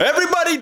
Track 05 - Vocal Everybody.wav